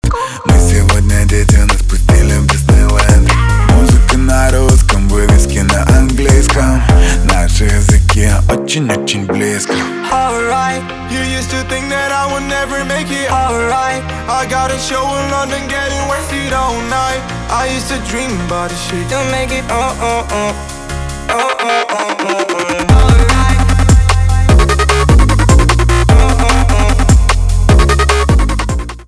русский рэп
битовые , басы , качающие
пианино